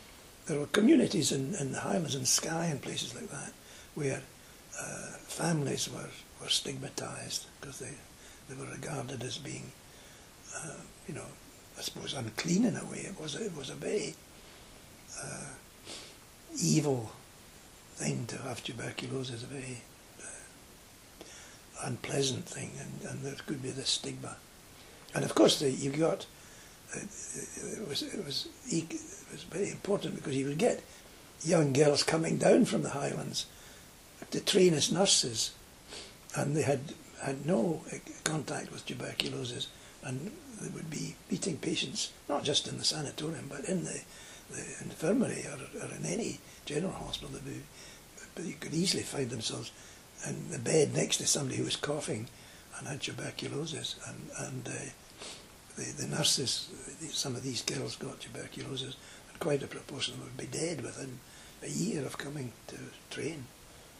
Here are two interview clips previously available on the NHS 60th anniversary website – the first on Orwell